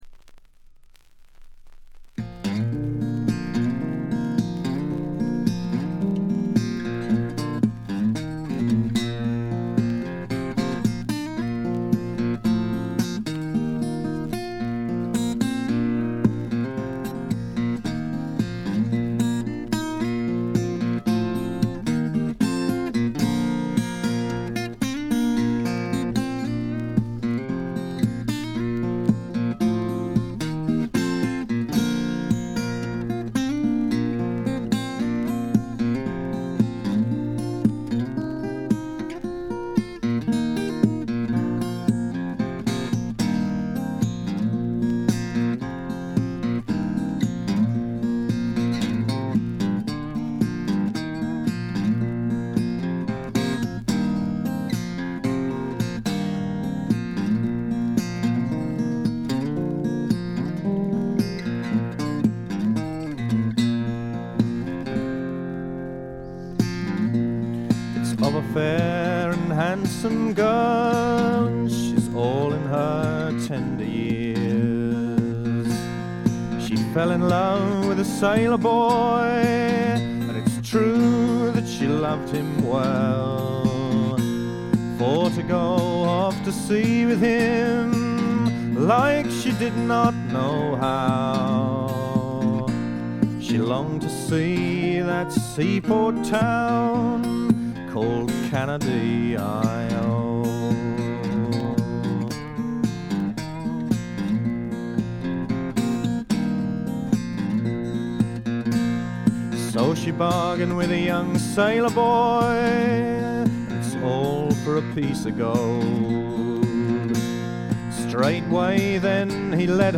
チリプチ少々。目立つノイズはありません。
芳醇で滋味あふれるヴォーカルにギターやフィドルも完璧です。
試聴曲は現品からの取り込み音源です。
Recorded At - Livingston Studios